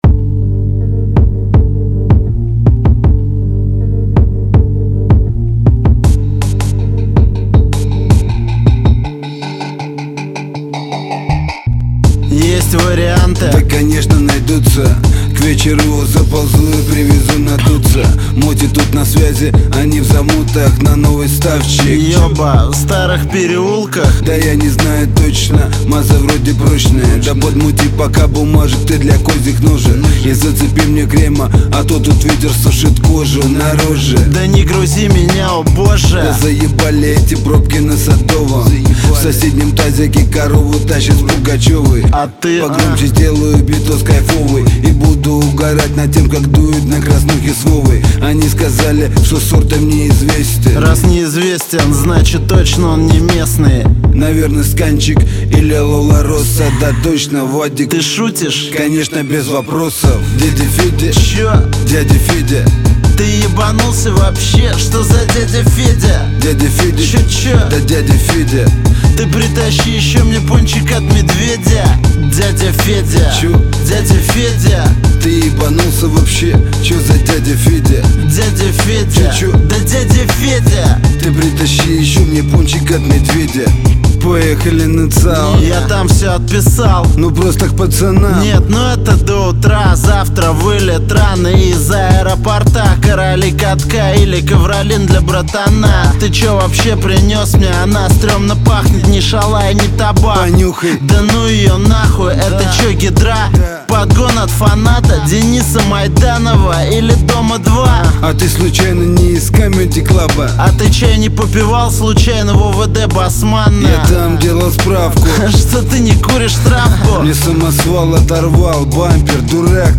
Жанр: Рэп (Хип-хоп)